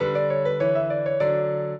minuet12-8.wav